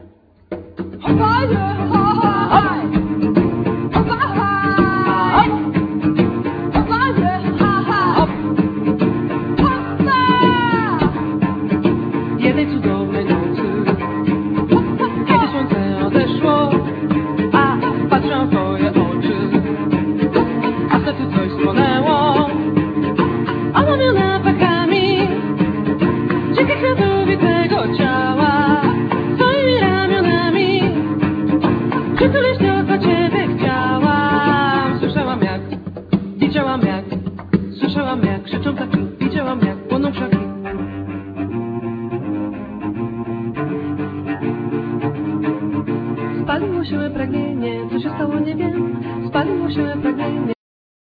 Cello,Vocals